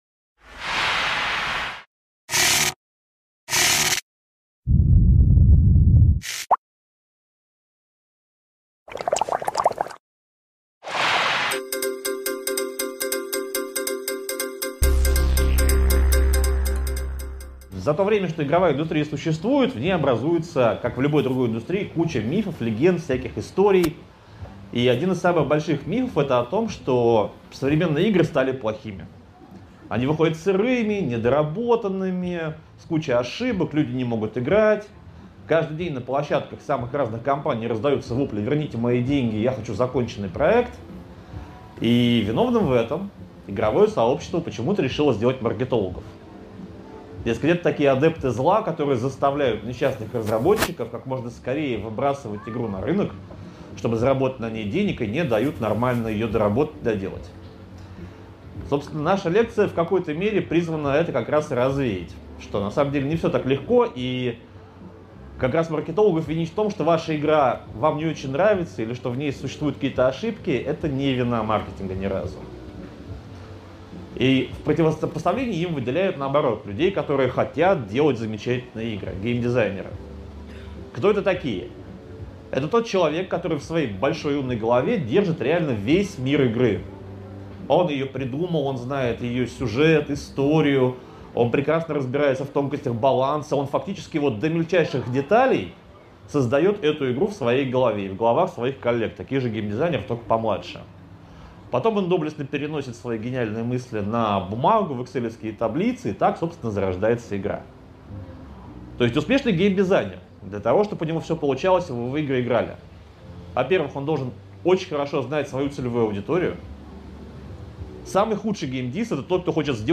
Аудиокнига Маркетинг и гейм-дизайн: любовь и деньги | Библиотека аудиокниг
Прослушать и бесплатно скачать фрагмент аудиокниги